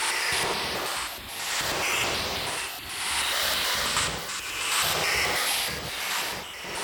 Index of /musicradar/stereo-toolkit-samples/Tempo Loops/140bpm
STK_MovingNoiseF-140_03.wav